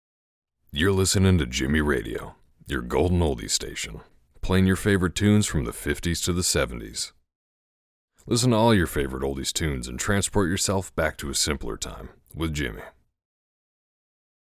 Radio Ad Demo
General American
Young Adult
Middle Aged